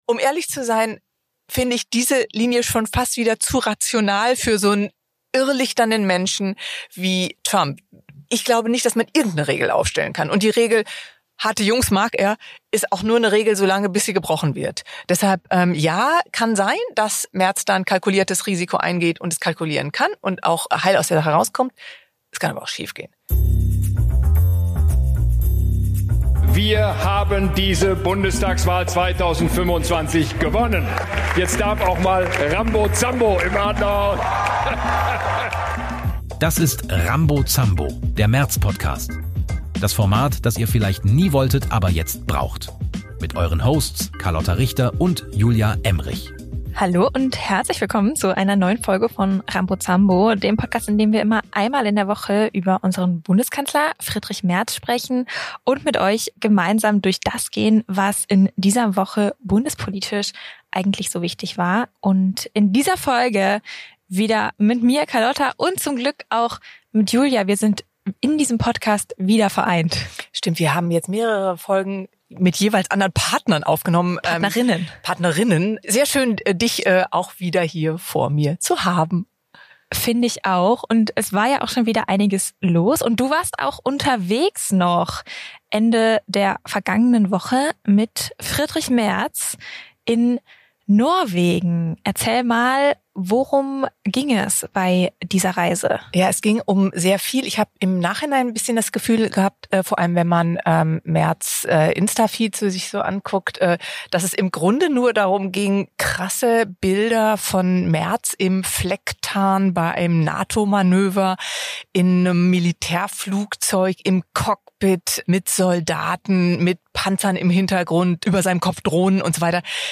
Zwei Frauen. Ein Kanzler. Immer freitags.